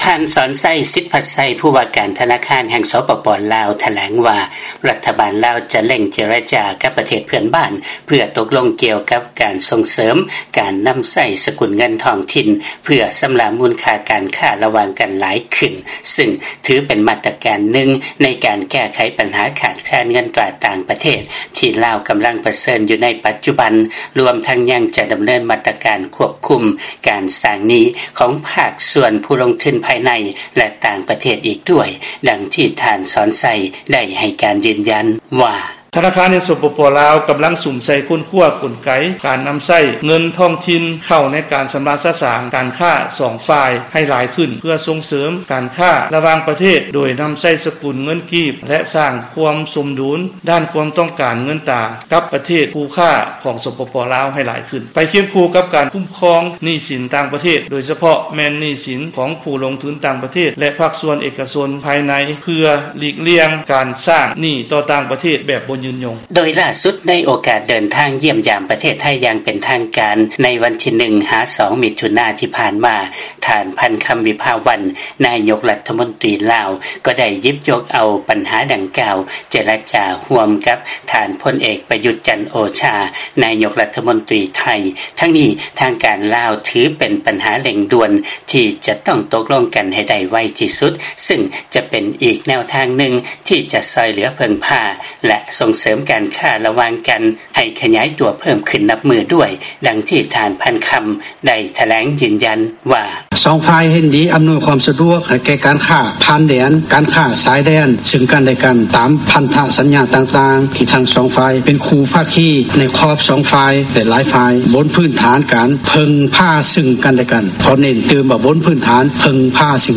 ເຊີນຟັງລາຍງານກ່ຽວກັບເລື້ອງ ລາວຈະເລັ່ງການເຈລະຈາກັບປະເທດເພື່ອນບ້ານເພື່ອຂໍໃຊ້ເງິນກີບຊຳລະຄ່າສິນຄ້າ